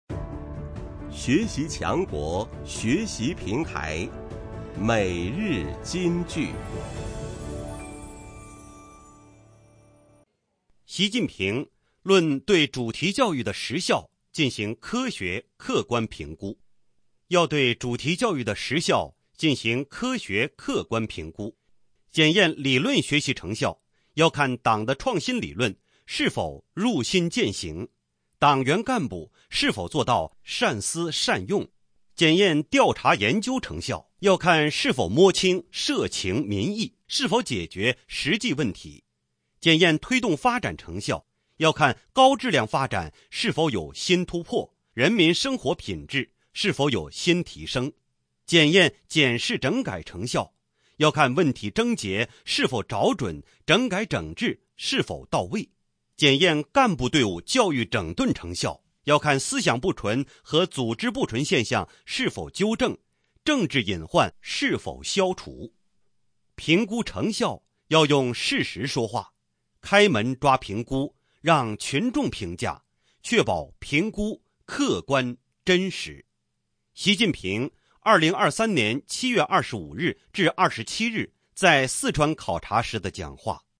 每日金句（朗读版）|习近平论对主题教育的实效进行科学、客观评估 _ 学习宣传 _ 福建省民政厅